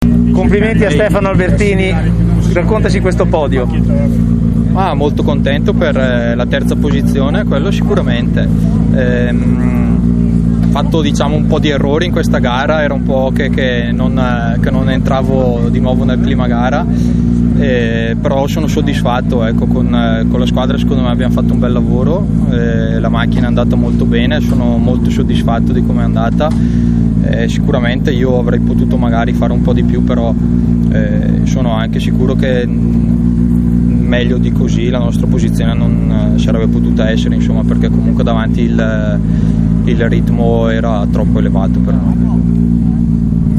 Interviste di fine rally